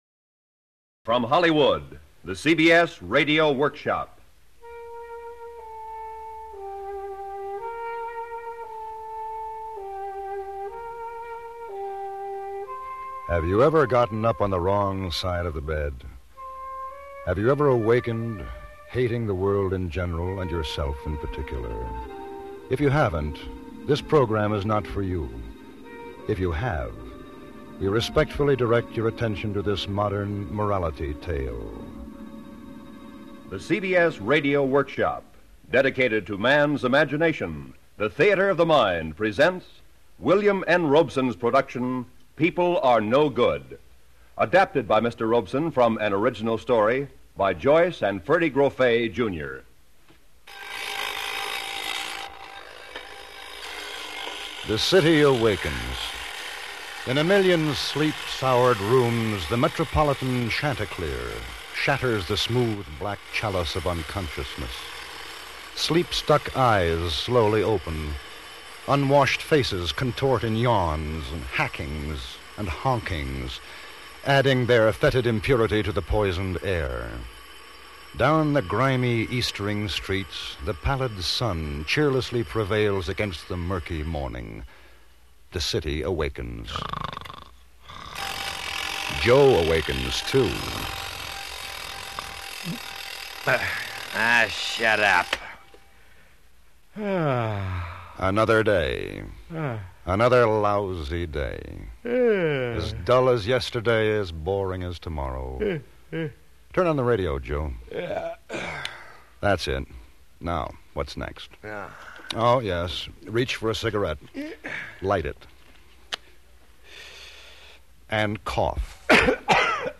CBS Radio Workshop with host and narrator William Conrad